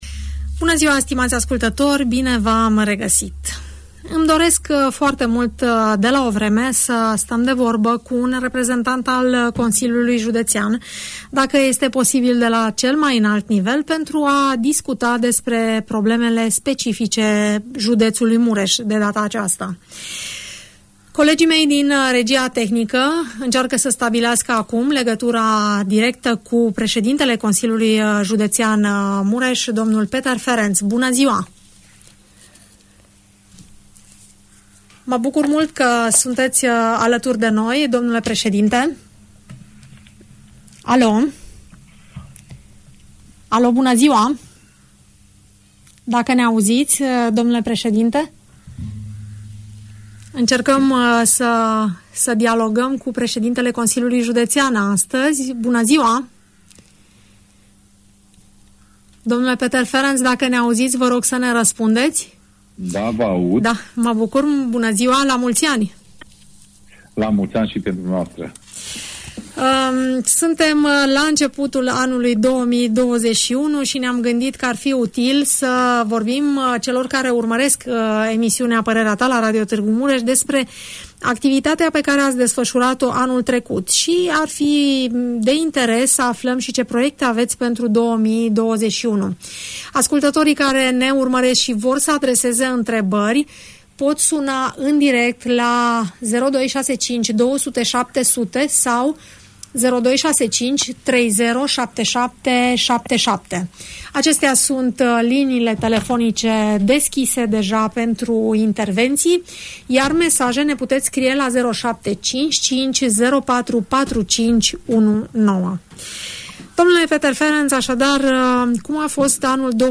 Peter Ferenc, președintele Consiliului Județean Mureș, sintetizează în emisiunea „Părerea ta”